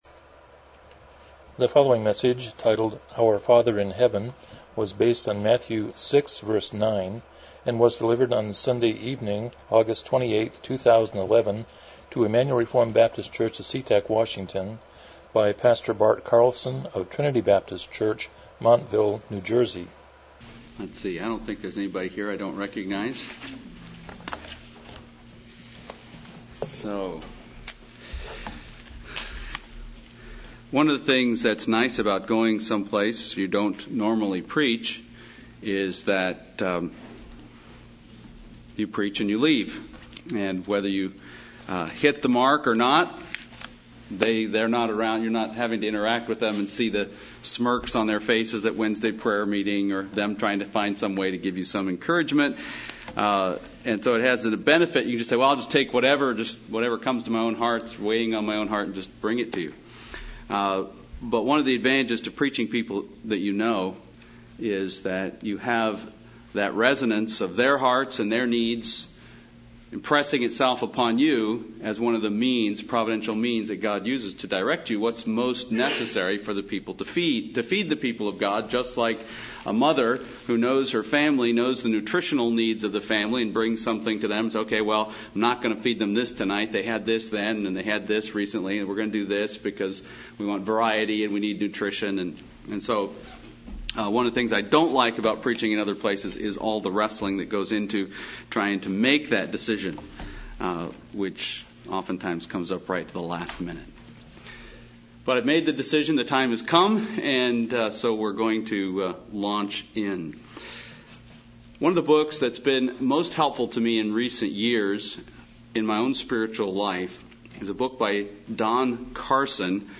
Passage: Matthew 6:9 Service Type: Evening Worship